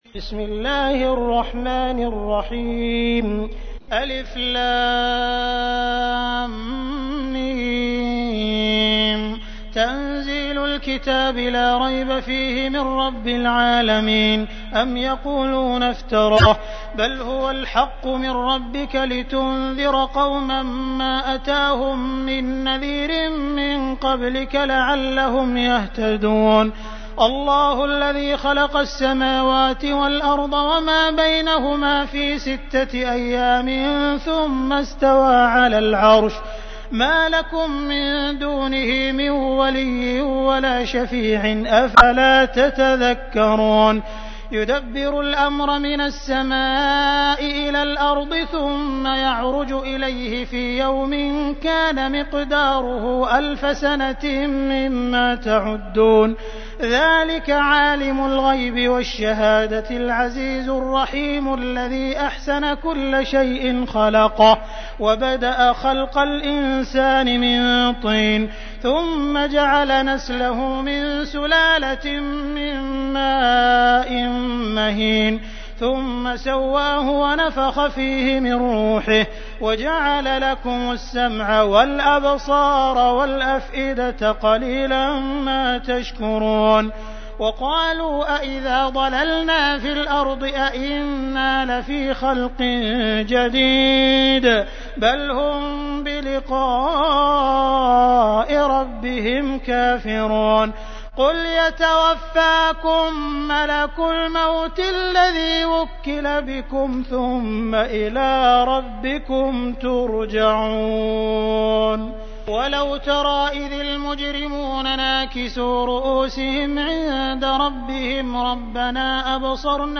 تحميل : 32. سورة السجدة / القارئ عبد الرحمن السديس / القرآن الكريم / موقع يا حسين